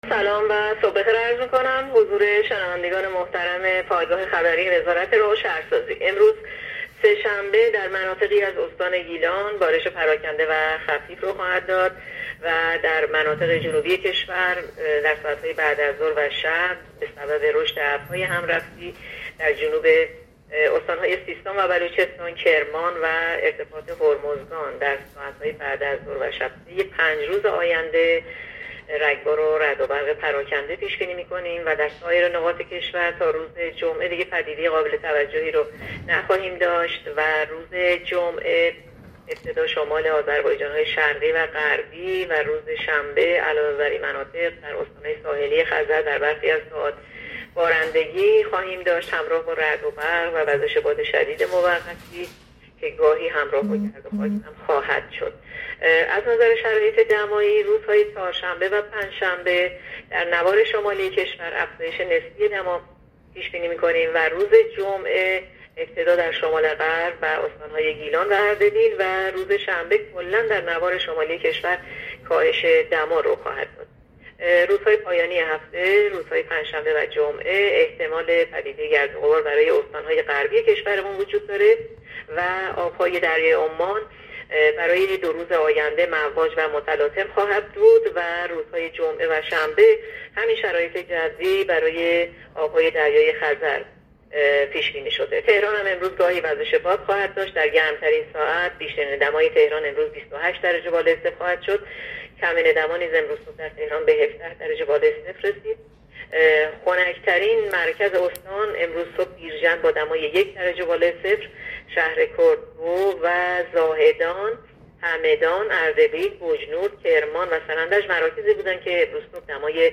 گزارش رادیو اینترنتی پایگاه‌ خبری از آخرین وضعیت آب‌وهوای ۱۵ مهر؛